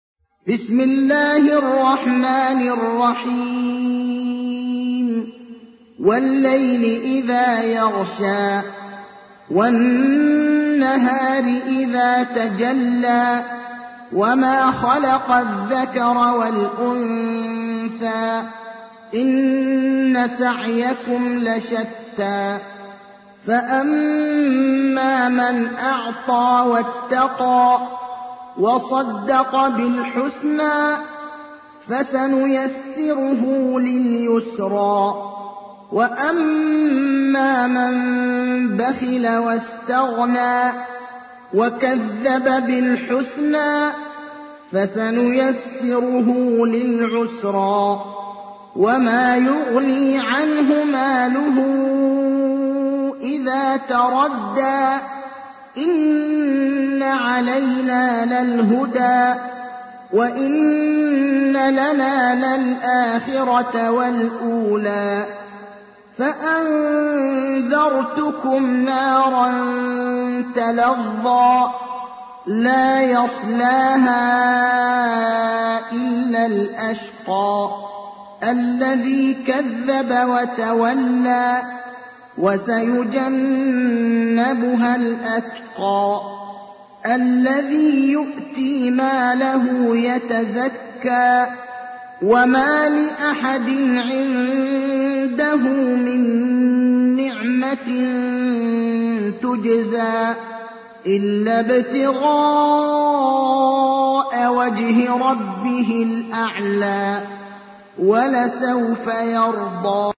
تحميل : 92. سورة الليل / القارئ ابراهيم الأخضر / القرآن الكريم / موقع يا حسين